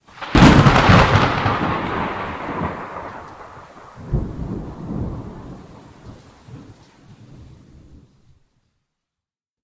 Lightning3.mp3